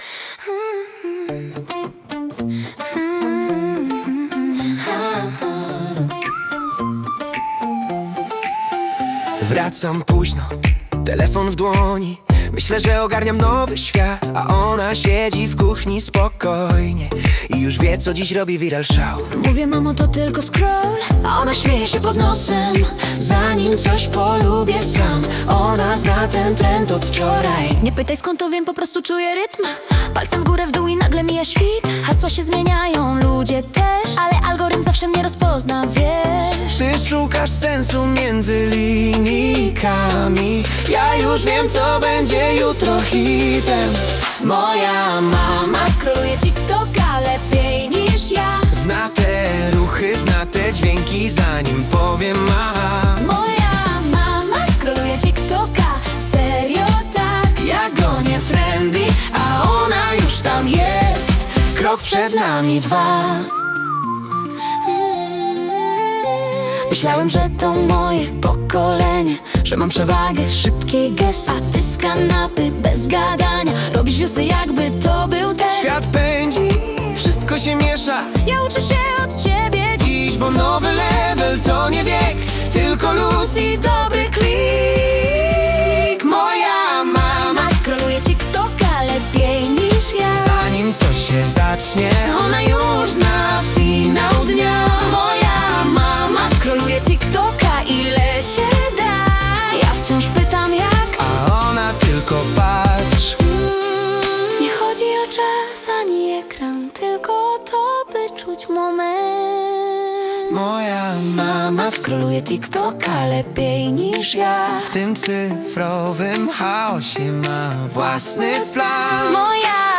Modern Pop, upbeat, catchy hook, duet male baryton & female light soprano, clean production, subtle electronic beat, radio-friendly, playful vibe, 110–120 BPM